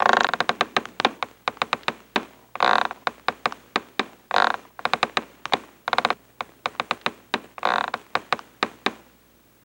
Rope Creaks Peaceful